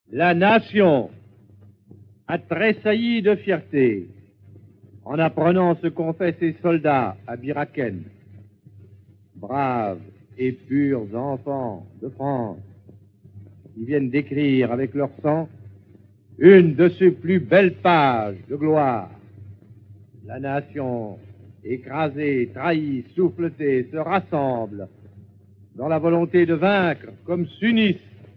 0% Extrait gratuit De Gaulle, extraits de discours de Charles De Gaulle Éditeur : Compagnie du Savoir Paru le : 2010 Nous tenons à préciser que 2 de ces enregistrements sont assez abimés, malgré les diverses restaurations numériques, du fait des conditions d'enregistrement (il y a 70 ans tout de même) et aussi à cause du brouillage allemand des ondes.